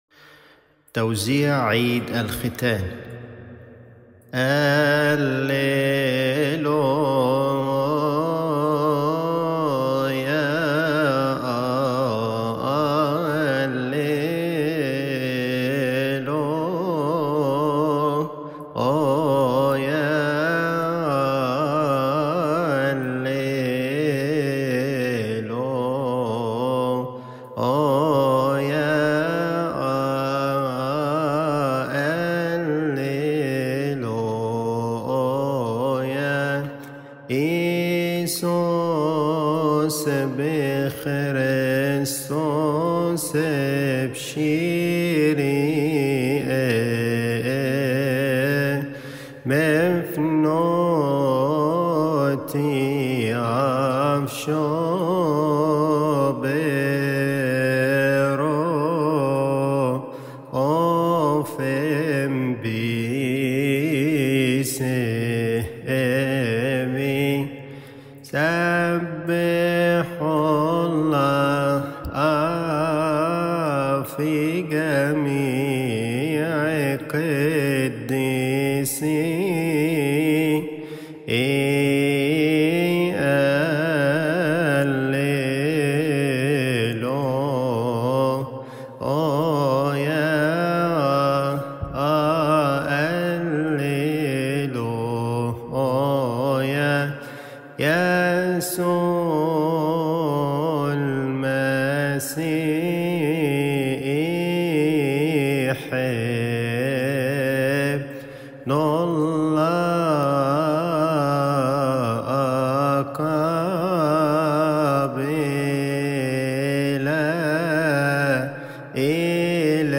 اللي التوزيع الفرايحي ومرد المزمور 150 الفرايحي لعيد الختان